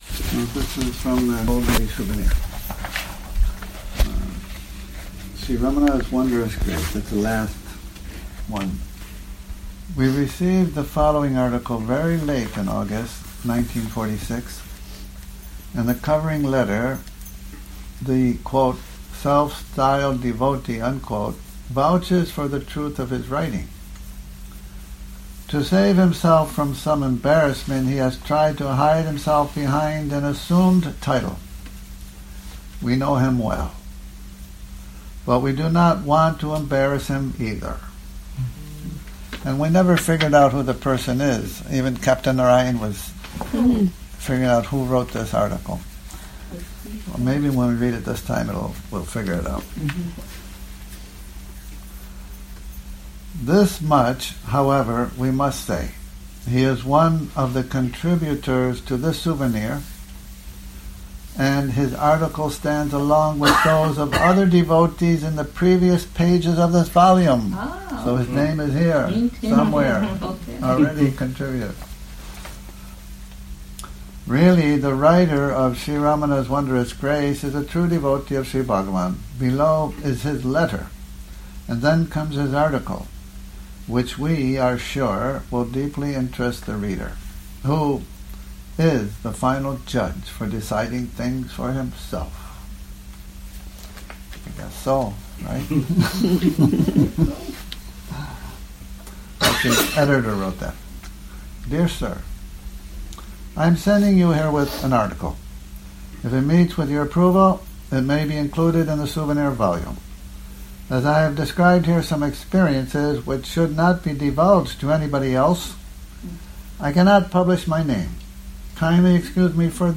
Morning Reading, 10 Sep 2019
a reading from 'Golden Jubilee Souvenir', Sri Ramana's Wondrous Grace